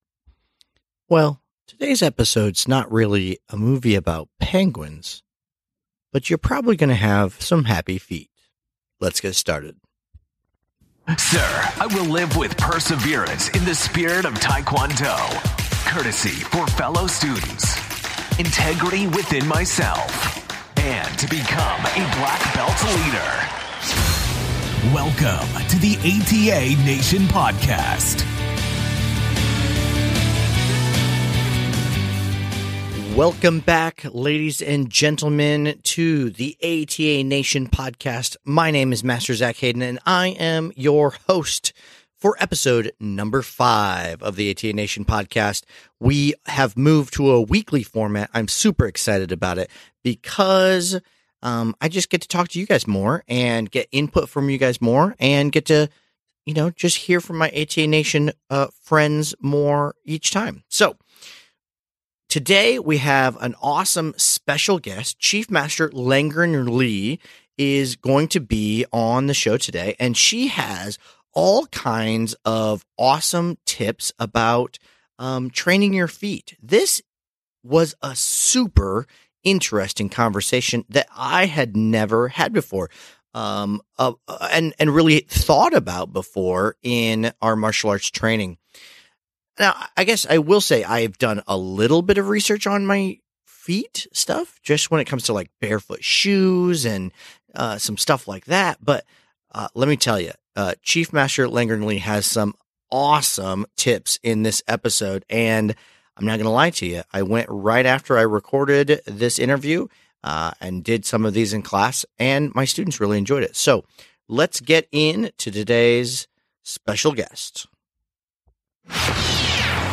Join us for a great interview